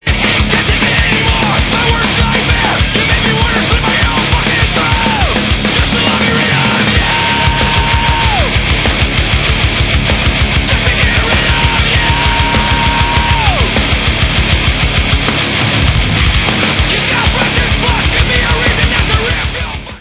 Rock - Speed/Thrash/Death Metal